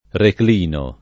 reclino [ rekl & no ]